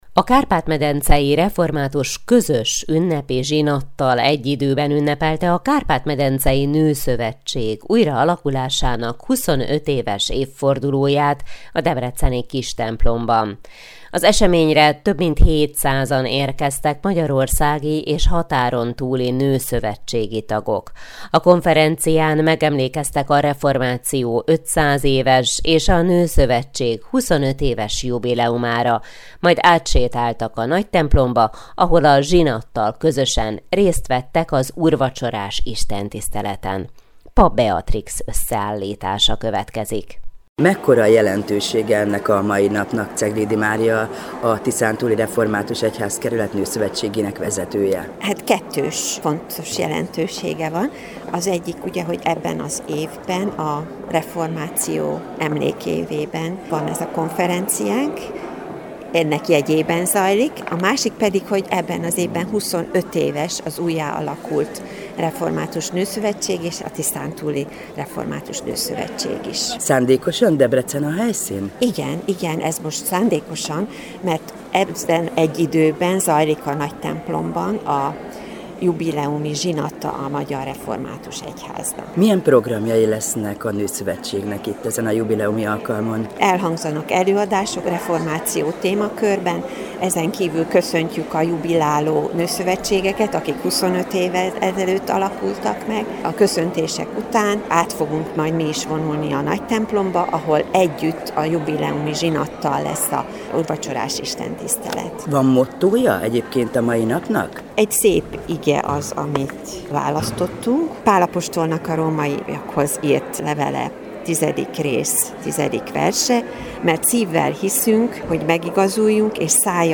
A Kistemplom megtelt református nőtestvérekkel, betöltötte a női énekhang a templomot, az éneklésben érezhetővé vált az ünnepi alkalom erejéből adódó meghatódottság.